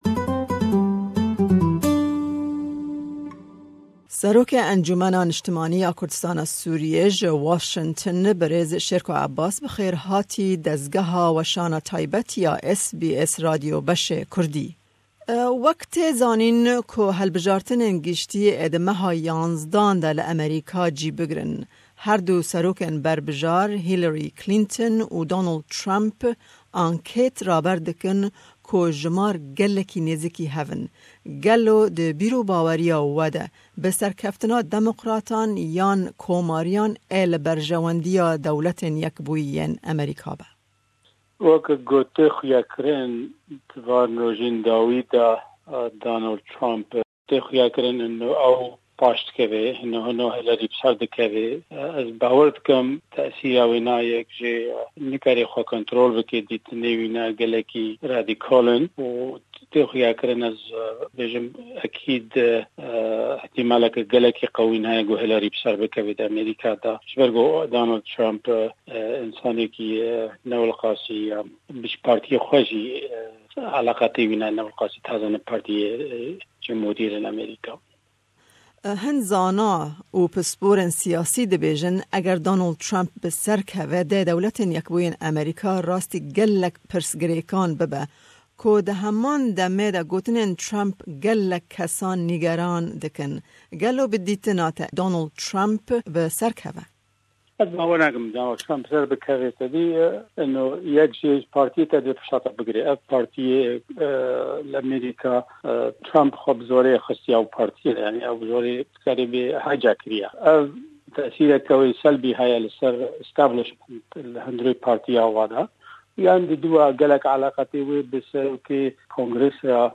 Me hevpeyvînek derbarî helbijartinên li DYA